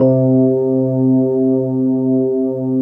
FEND1L  C2-L.wav